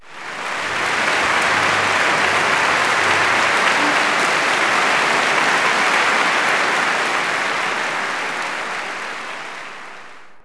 clap_035.wav